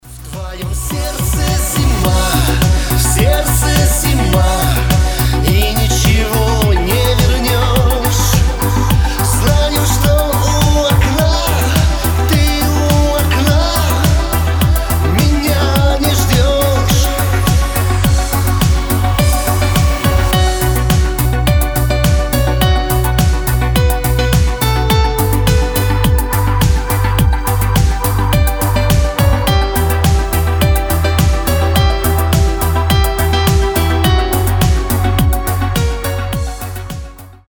Шансон рингтоны
романтичные
грустные